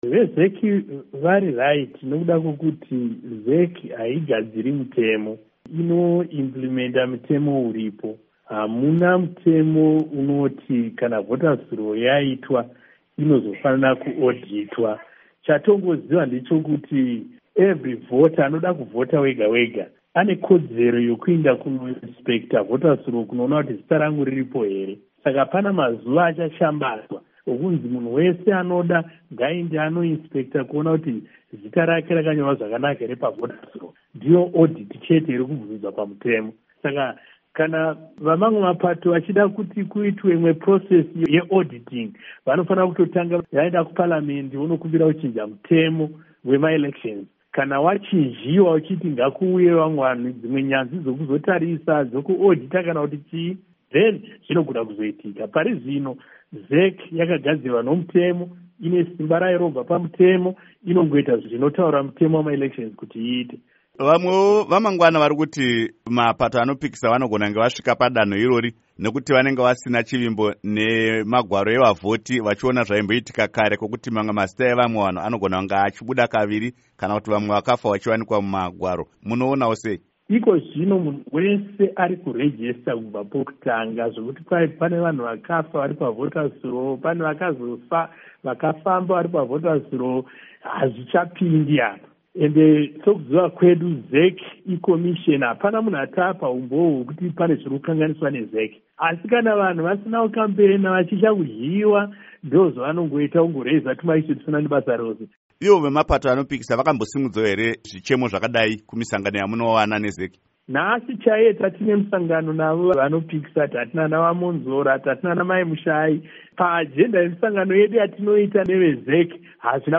Hurukuro naVaMunyaradzi Paul Mangwana